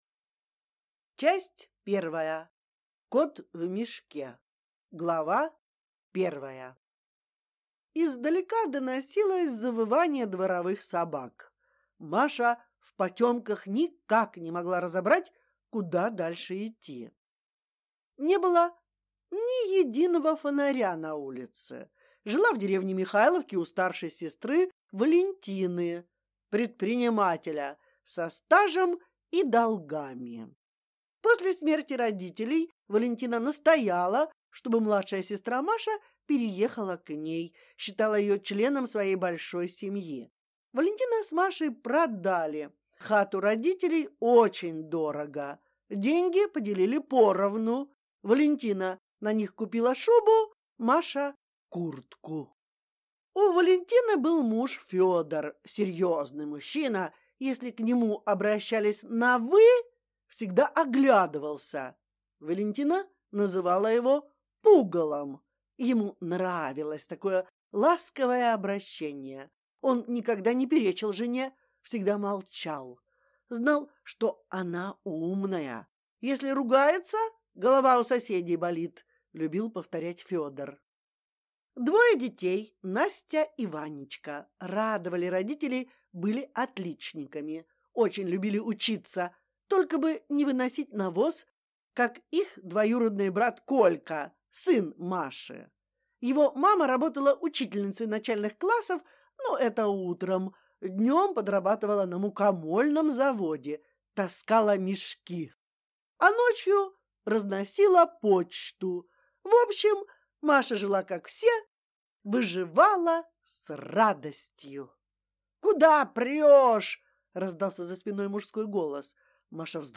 Аудиокнига В погоне за мужем!